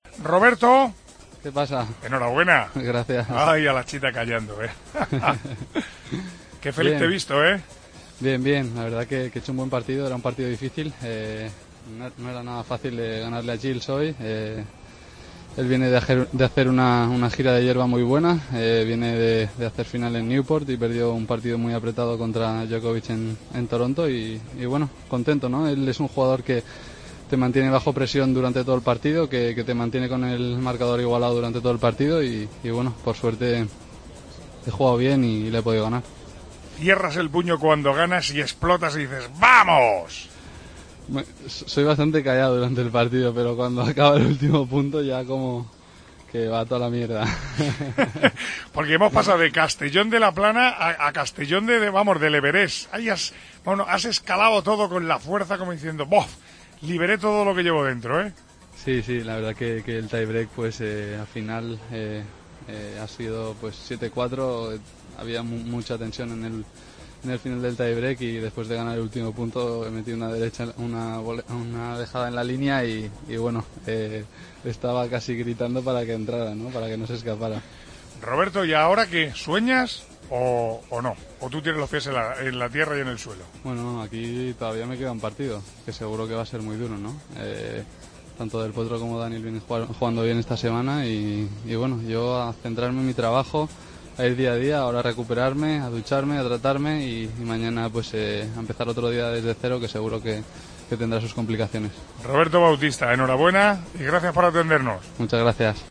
Hablamos con el tenista español tras su clasificación a cuartos: "Era un partido difícil, el viene de hacer gira de hierba muy buena. Es un jugador que te mantiene con presión. Por suerte he jugado bien y le he podido ganar. Soy bastante callado durante el encuentro pero cuando acaba el partido exploto".